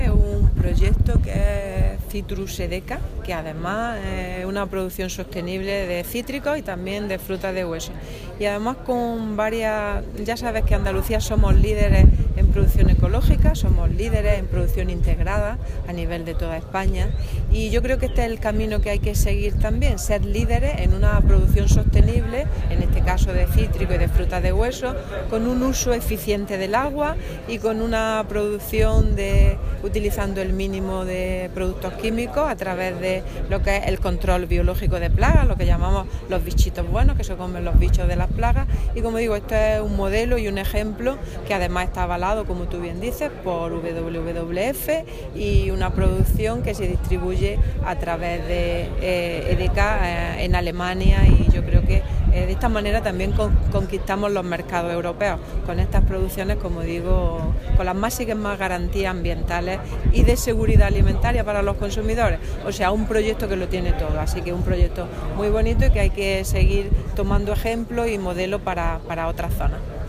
La consejera Carmen Ortiz, en la finca El Esparragal en Gerena.
Declaraciones consejera proyecto Zitrus Edeka